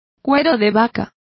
Complete with pronunciation of the translation of cowhides.